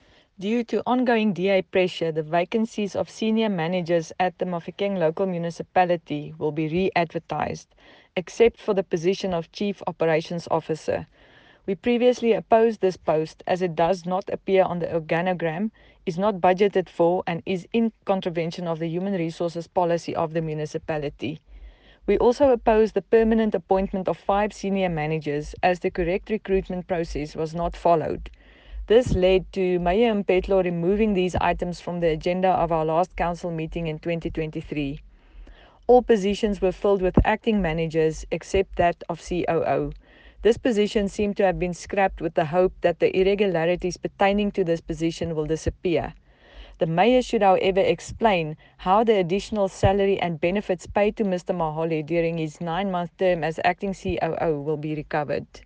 Note to Broadcasters: Please find linked soundbites in
Cllr-Arista-Annandale-Mahikeng-Irregular-Appointments-Eng.mp3